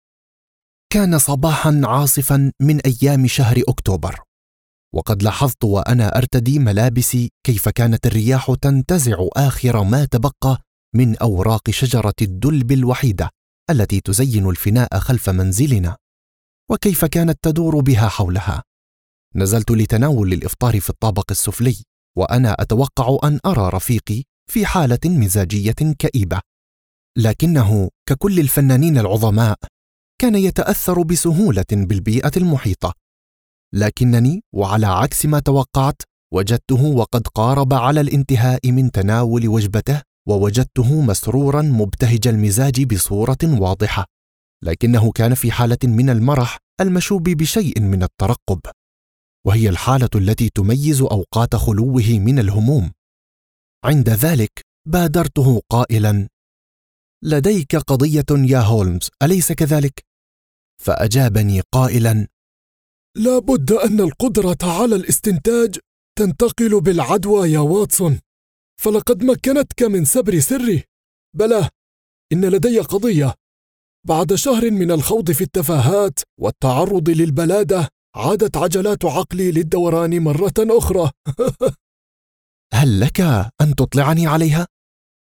Audiobook sample
Novel sample recorded for a platform
Audiobook_sample.mp3